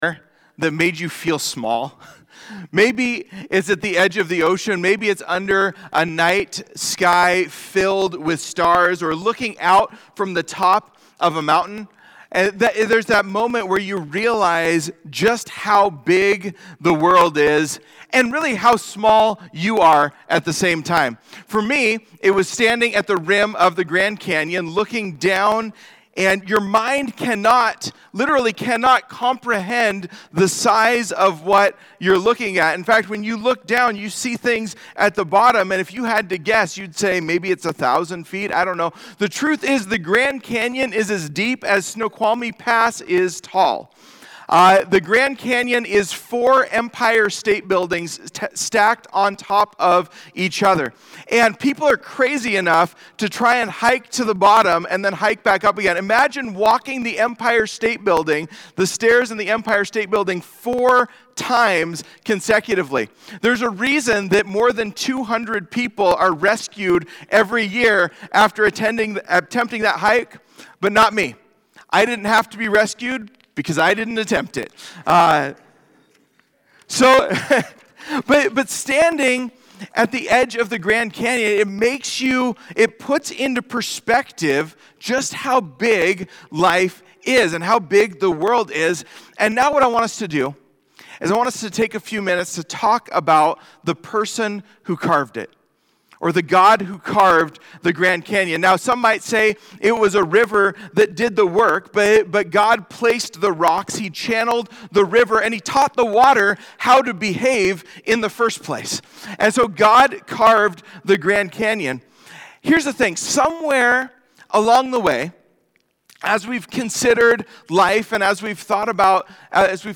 When our view of God grows bigger, our worship goes deeper. In this opening message, Majestic and Merciful, we look at Deuteronomy 10, where Moses reminds Israel who God truly is — the Lord of heaven and earth who is both powerful and compassionate.